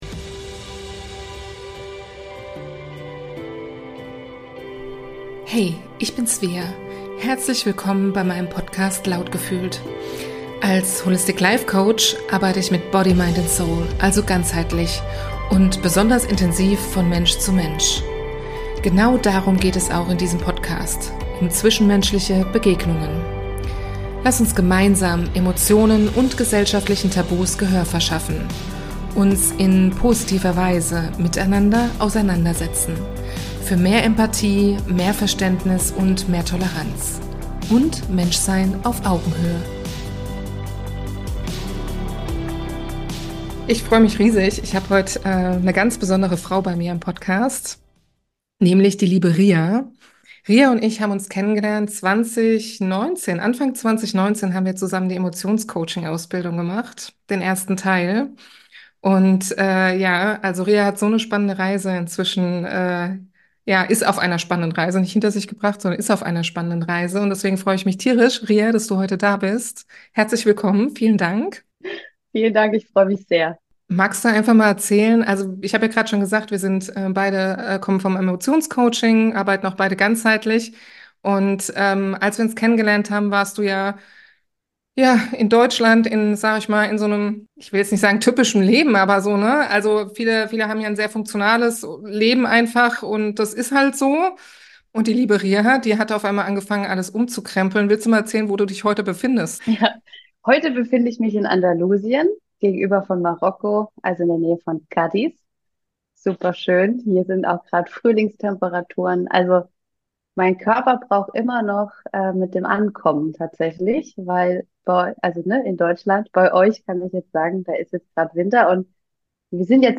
Interview ~ lautgefühlt – Emotionen, Menschsein, Miteinander Podcast